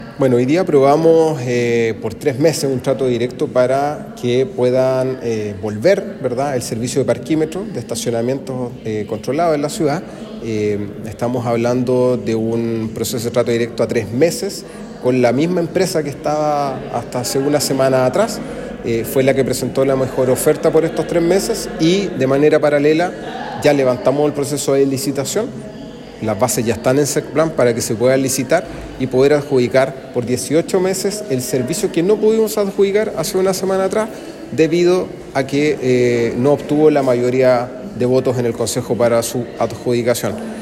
El presidente del Concejo Municipal, Miguel Arredondo, explicó que esta decisión se tomó luego de no alcanzar consenso en la licitación presentada la semana anterior, proceso que continúa en revisión en Secplan.